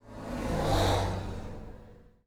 car4.wav